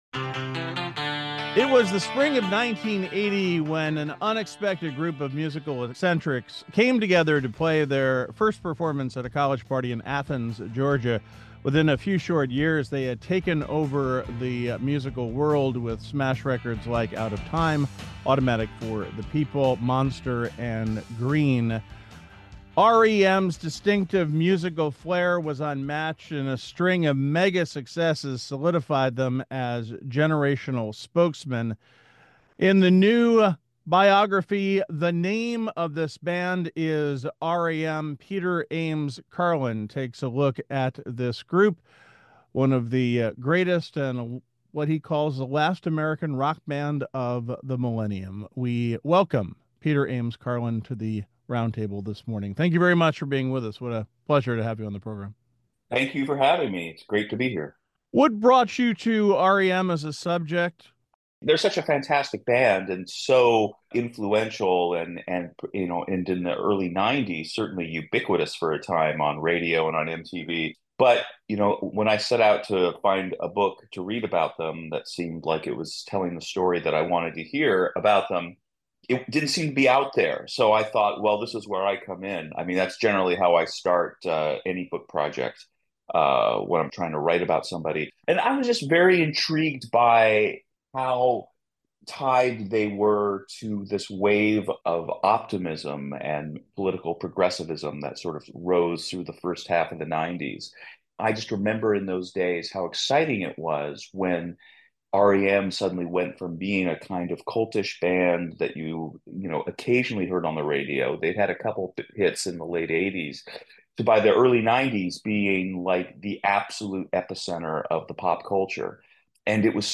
WAMC's The Roundtable is an award-winning, nationally recognized eclectic talk program.